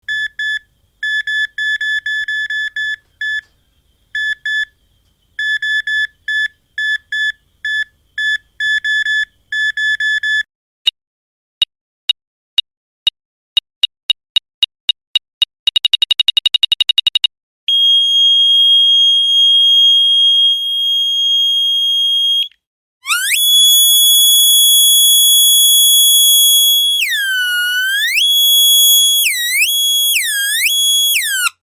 Звуковая серия металлоискателя для монтажа поиск предметов